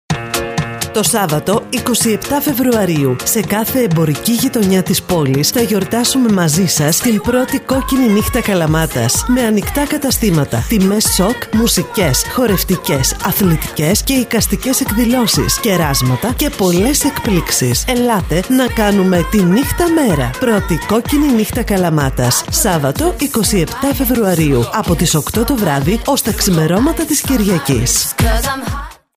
ΚΟΚΚΙΝΗ_ΝΥΧΤΑ_radio_spot.mp3